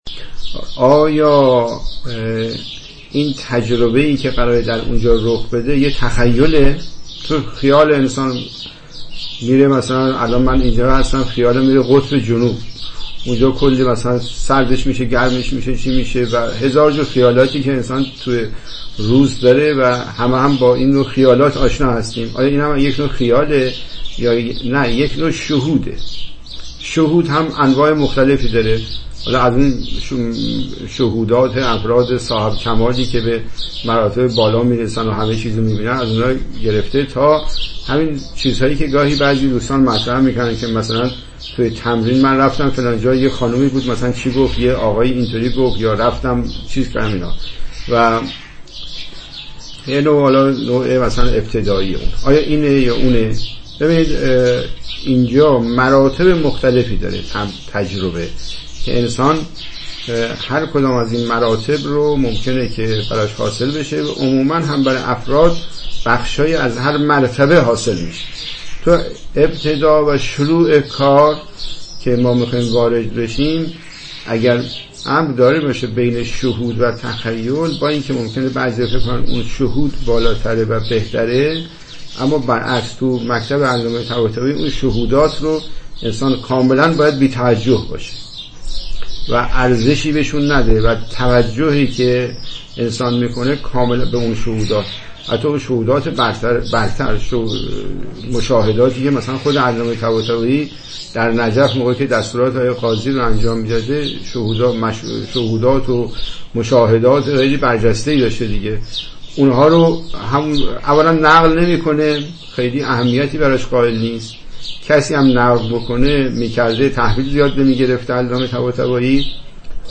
گفت‌وگو دربارۀ رمضان (انجام تمرین روزه‌داران کوهستان یمن) (۳)